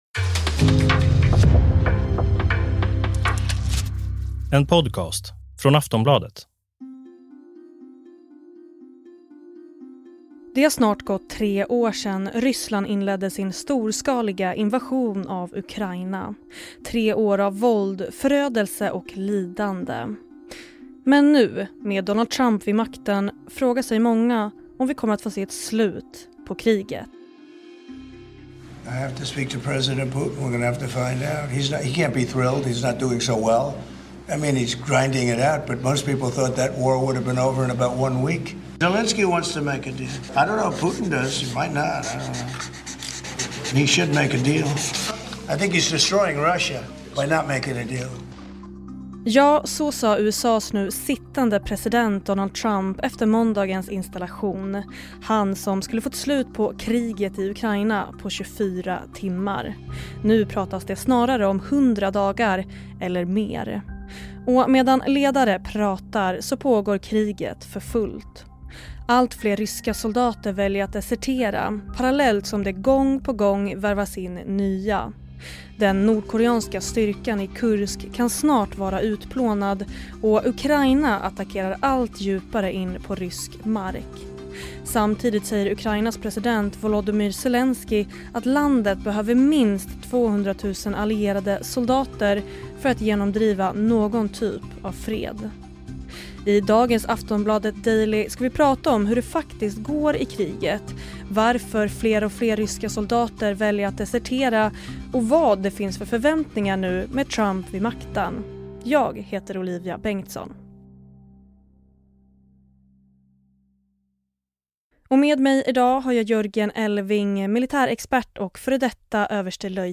Klipp i avsnittet: CBS News.